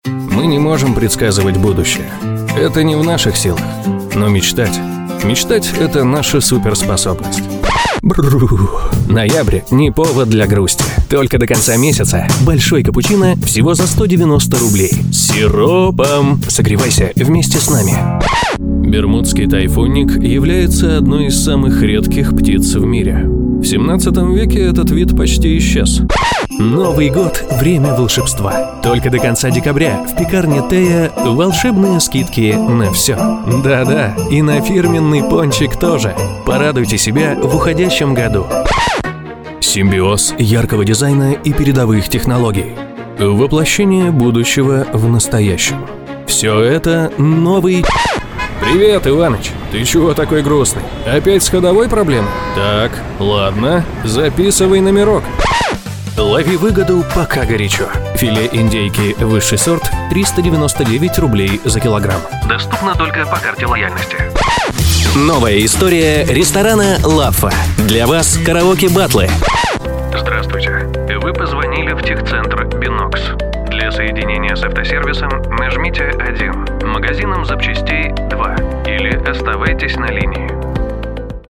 Тракт: Оборудованная кабина для записи. Микрофон: recording tools MC-900 Карта: focusrite scarlett 2i2 3rd gen
Демо-запись №1 Скачать